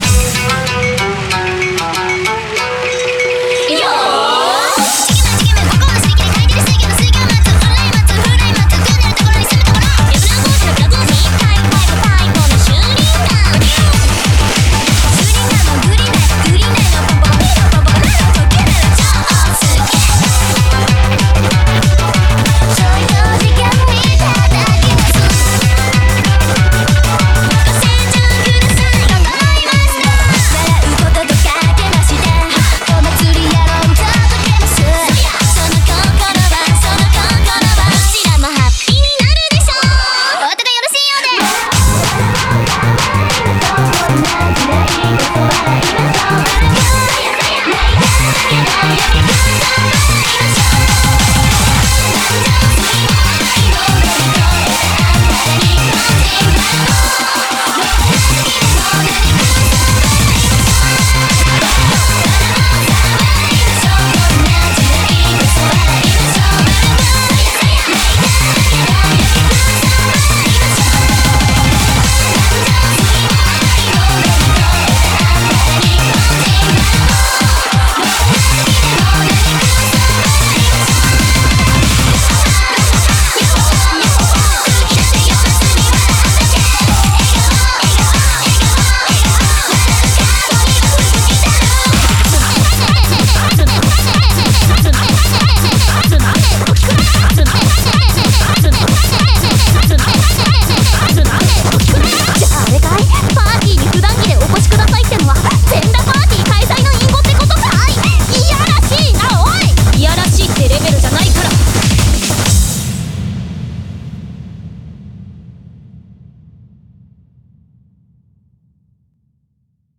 BPM190
MP3 QualityMusic Cut